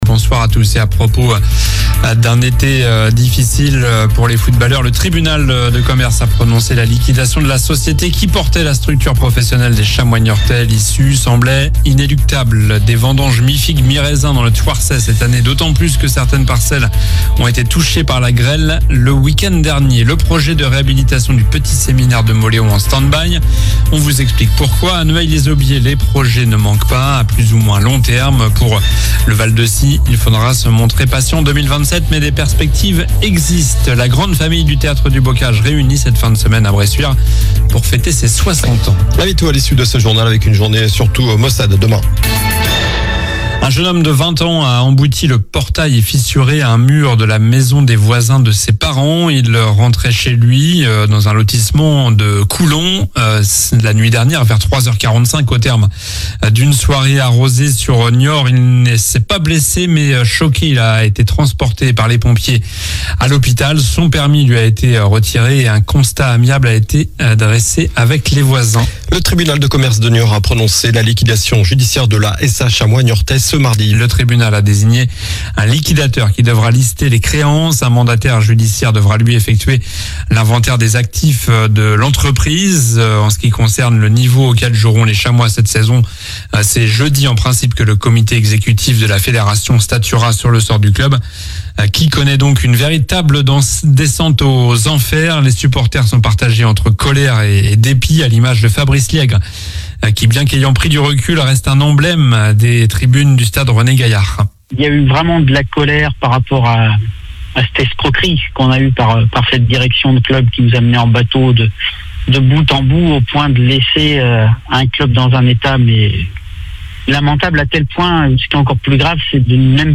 Journal du mardi 13 septembre (midi)